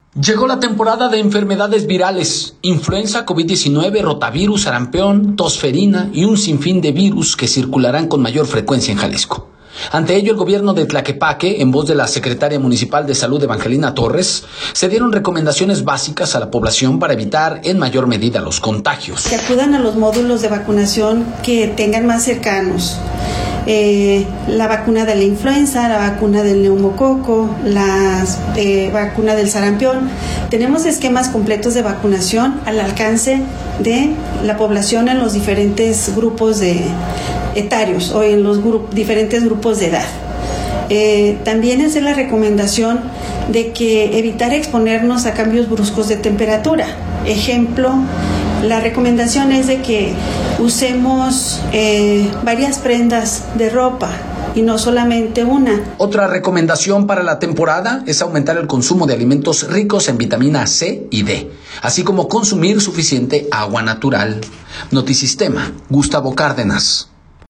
Ante ello, el gobierno de Tlaquepaque, en voz de la Secretaría Municipal de Salud, Evangelina Torres, dio recomendaciones básicas a la población para evitar en mayor medida los contagios.